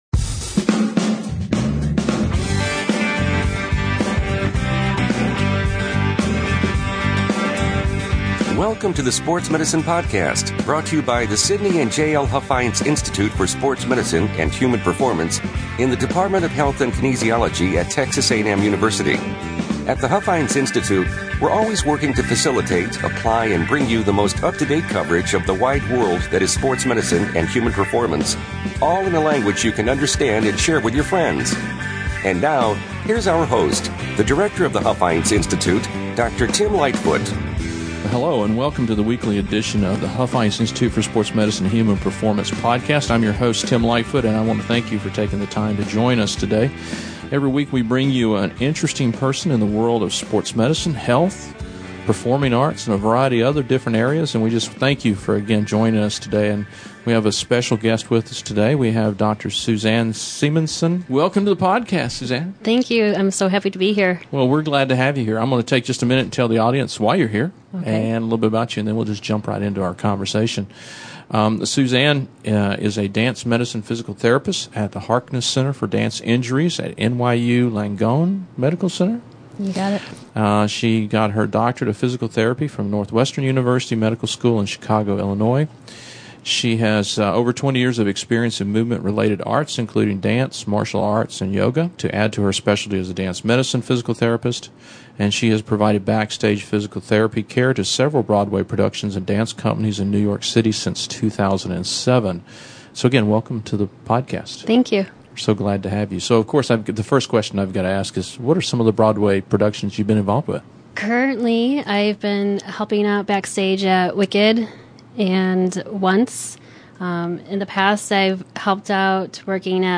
We have a great conversation regarding injuries and rehabilitation of dance performers, especially on the Broadway theater scene. This is a great peek behind the 'theater curtain' as to how these athletes perform and rehabilitate when they are injured.